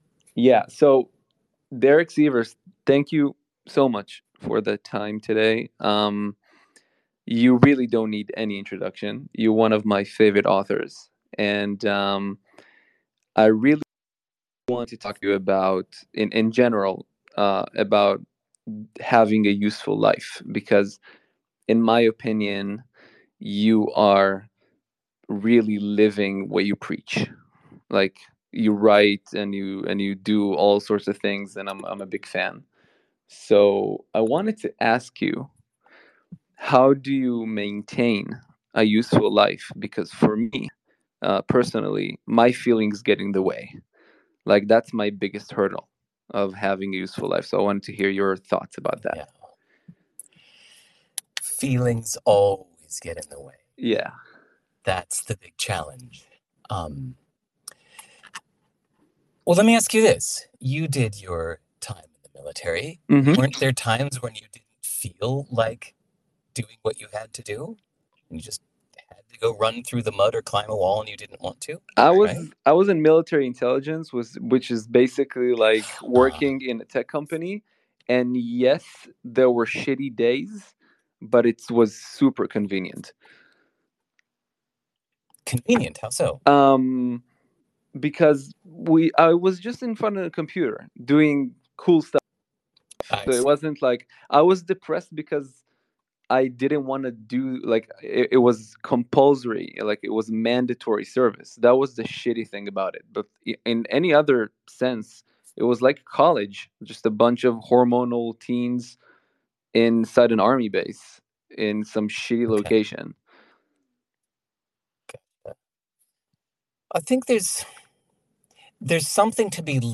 Interviews:
Audio-only, live on X Spaces, about distractions, fears, travel, and more.